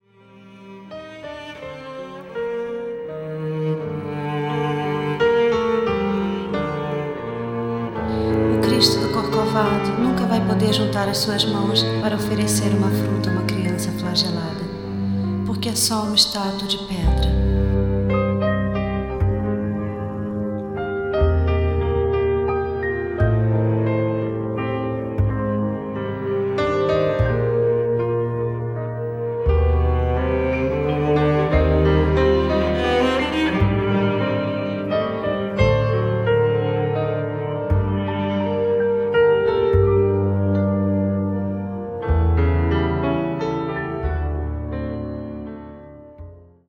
avec voix féminine